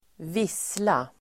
Uttal: [²v'is:la]